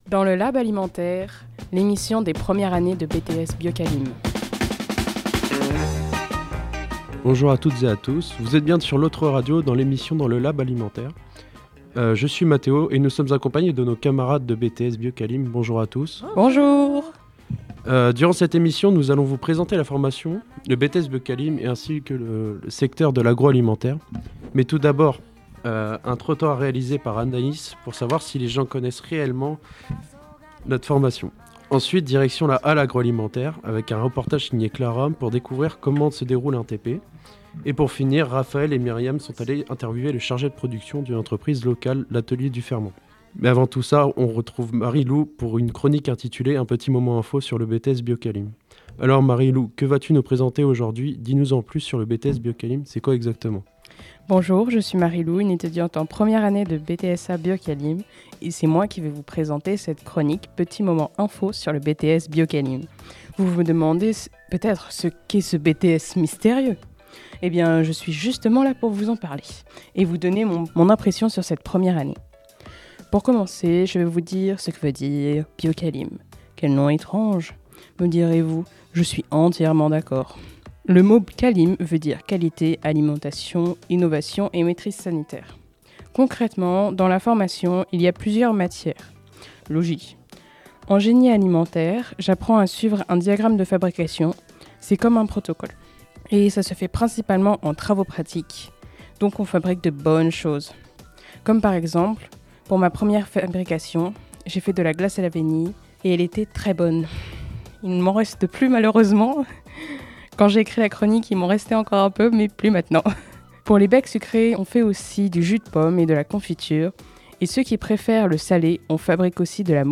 Les BTS bioqualim du Lycée Agricampus de Laval vous proposent une émission à la découverte de leur formation !